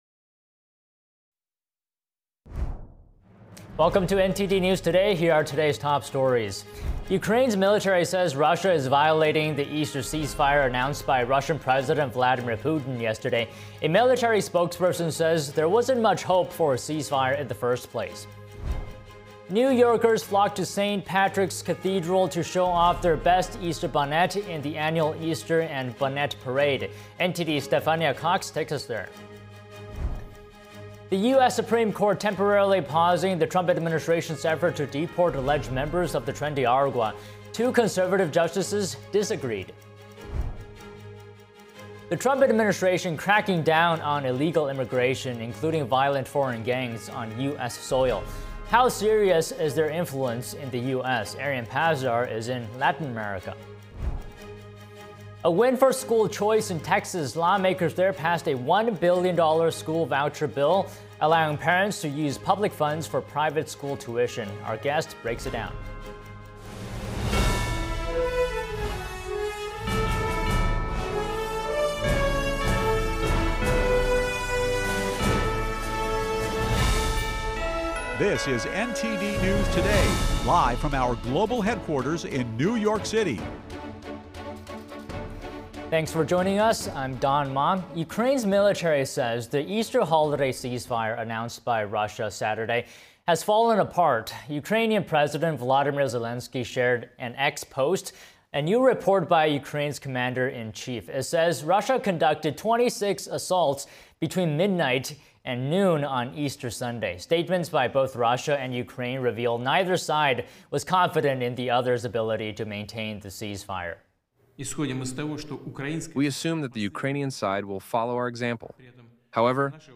NTD-News-Today-Full-Broadcast-April-20-audio-converted.mp3